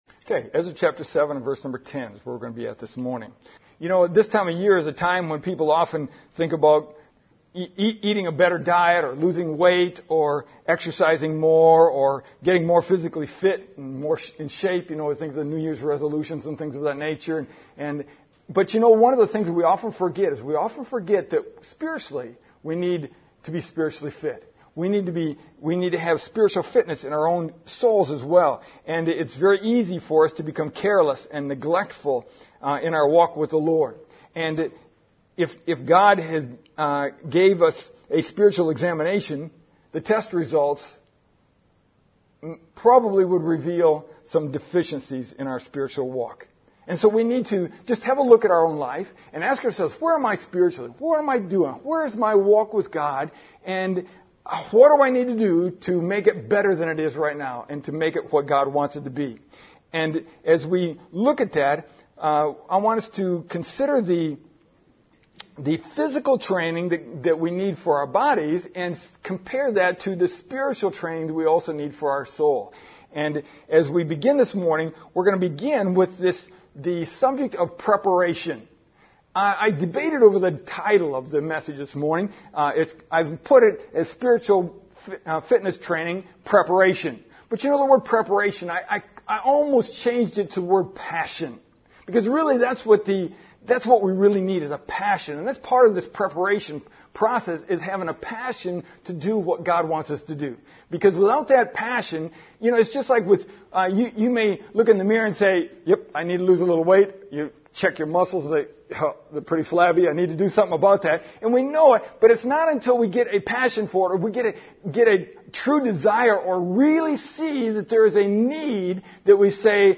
We will soon get back to James and Colossians, but for the first two weeks of the year in the morning and evening services, I us to focus on our spiritual fitness.